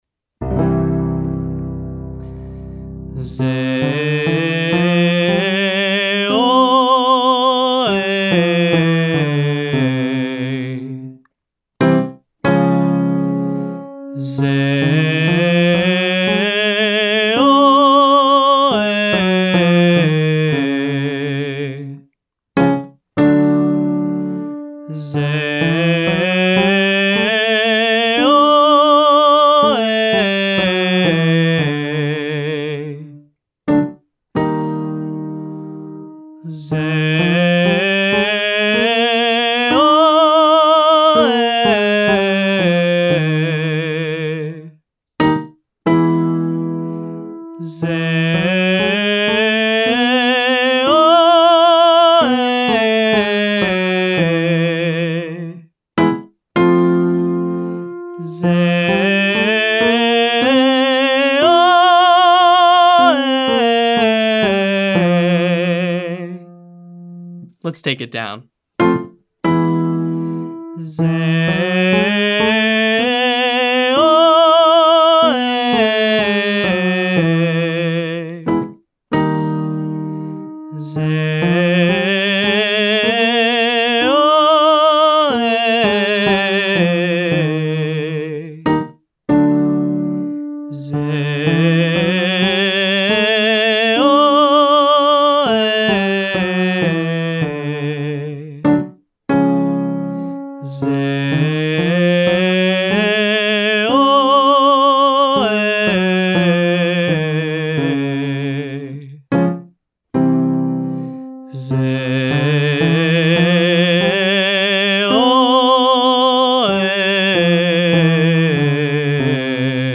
Exercise 1: Classical Tone (Zay, Oh, Ah 1,2,3,4,5-8-5,4,3,2,1)
A warm up for a Classical concert or an Opera is going to require lots of support, back placed resonance, and vibrato.